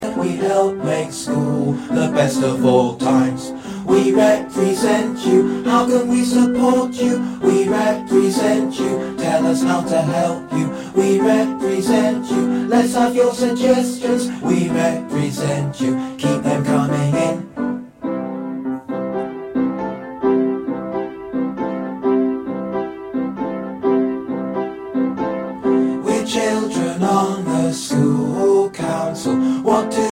Listen to the vocal track.